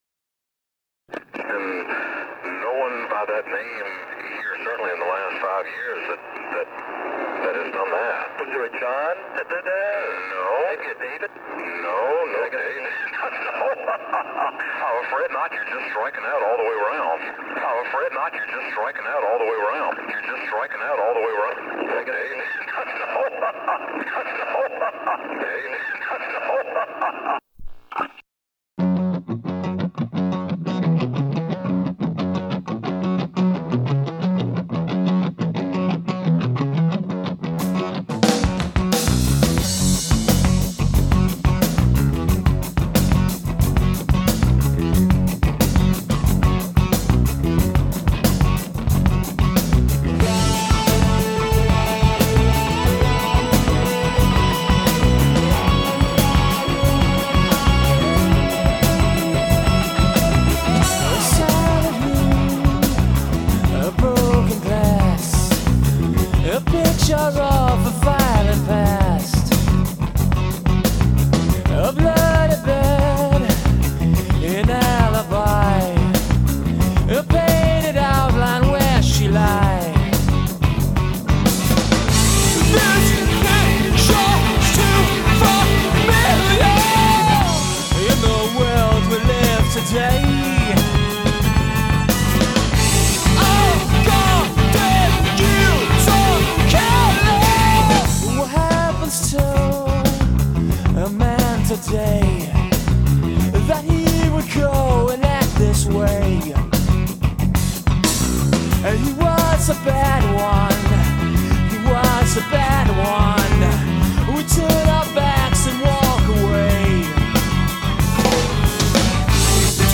Progressive Pop